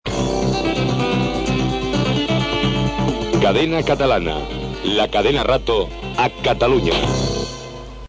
195df795d123c5dae7c85ea7de2fa4a399e4adb1.mp3 Títol Cadena Rato Catalunya Emissora Cadena Catalana Barcelona Cadena Cadena Catalana Cadena Rato Titularitat Privada estatal Descripció Identificació de Cadena Catalana - Cadena Rato a Catalunya.